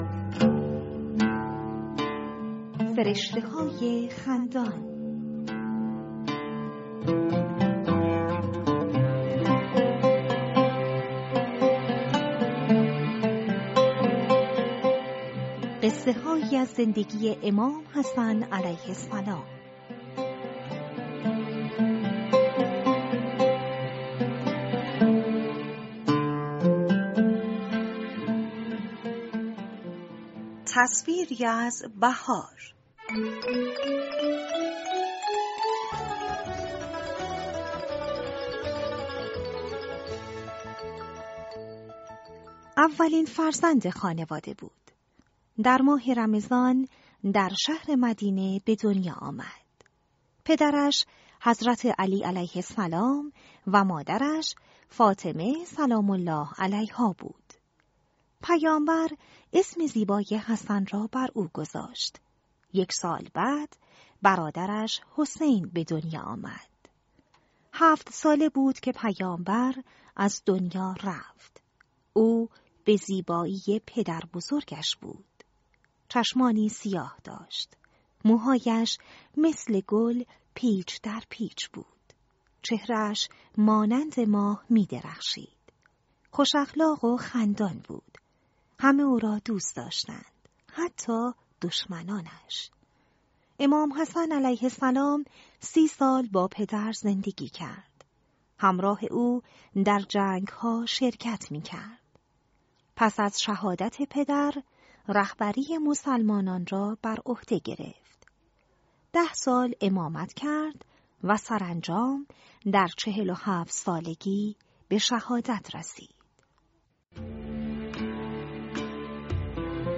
بفرمایید قصه …کتاب صوتی فرشته‌های خندان(قسمت اول: تصویری از بهار)
# کتاب صوتی # سبک زندگی # حضرت امام حسن مجتبی علیه السلام # قصه کودک